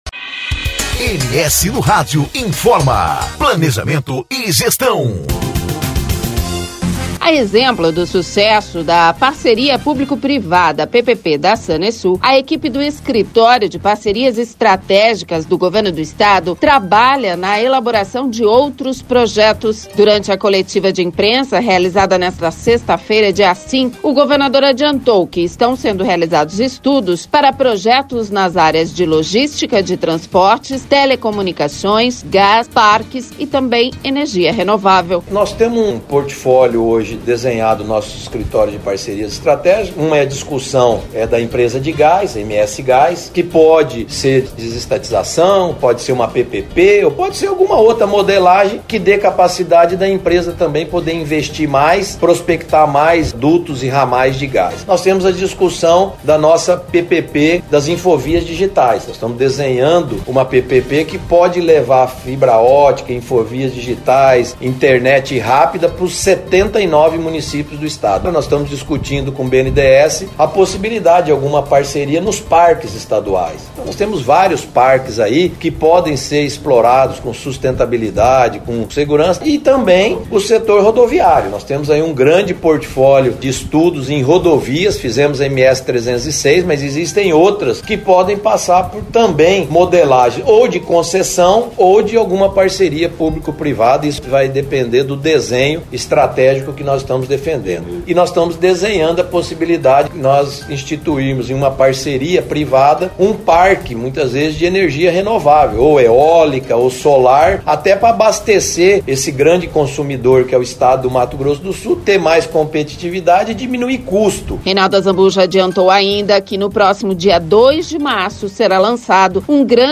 Durante a coletiva de imprensa, realizada nesta sexta-feira, dia 05, o governador adiantou que estão sendo realizados estudos para projetos nas áreas de Logística de Transportes, Telecomunicações, Gás, Parques e Energia Renovável.